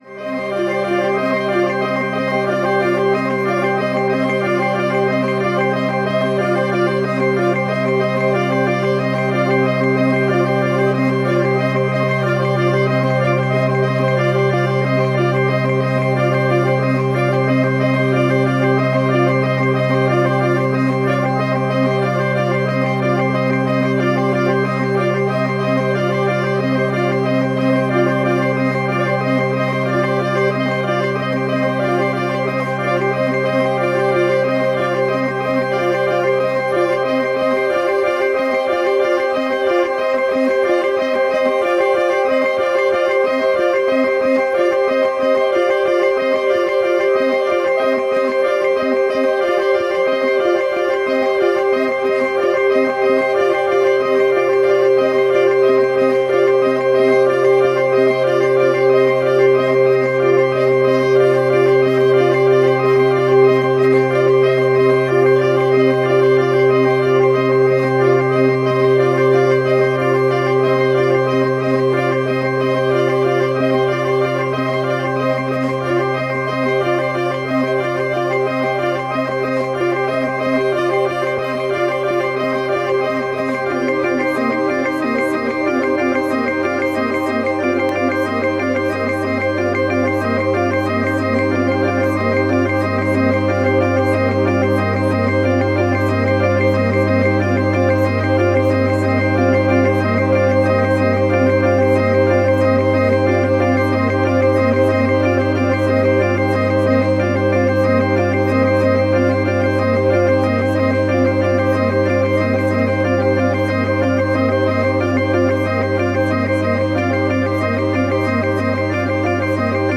minimalist composition